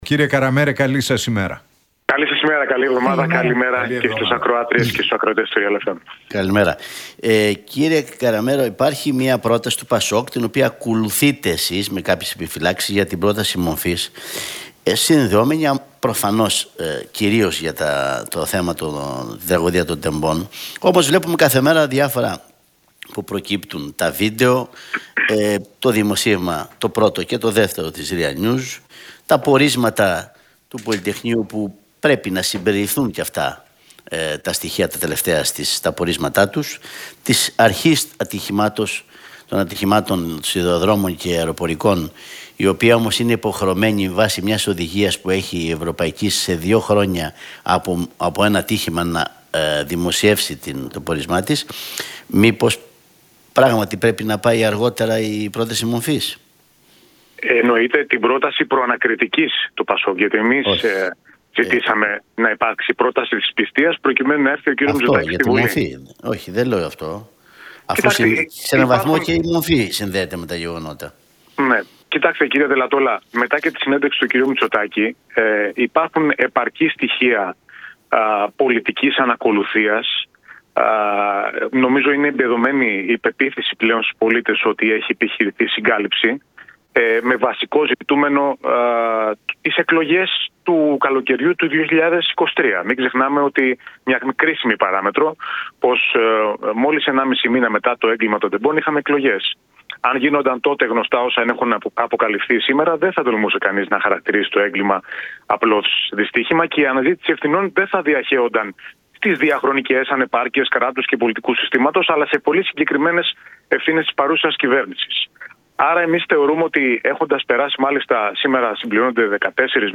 από την συχνότητα του Realfm 97,8.